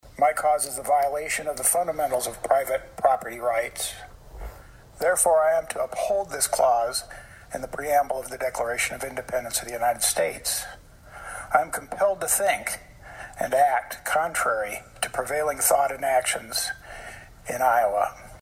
Senator Campbell requested a point of personal privilege Wednesday to address his fellow senators.